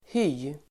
Ladda ner uttalet
Uttal: [hy:]